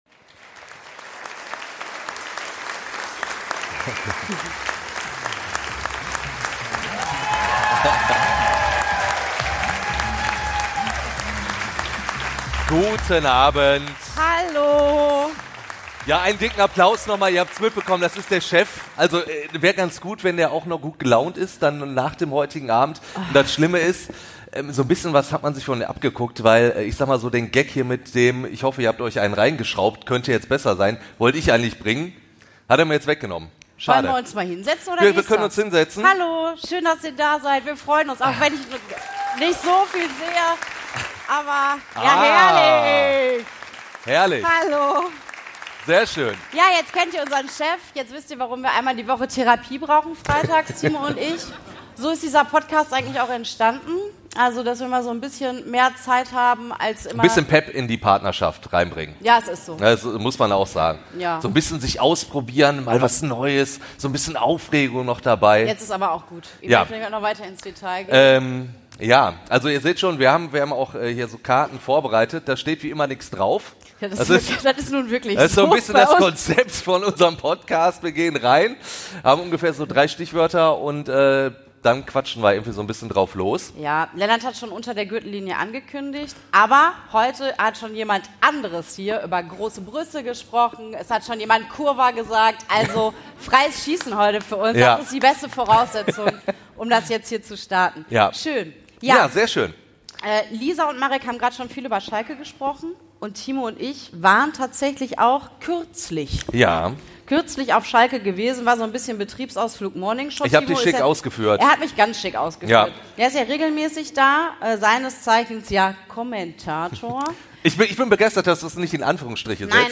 SONDERFOLGE: Live-Podcast aus der Kaue Gelsenkirchen ~ Dat kannste echt 'n andern sagen Podcast
In dieser Woche gibt es nämlich den Mitschnitt von unserem Live-Podcast in Kaue in Gelsenkirchen.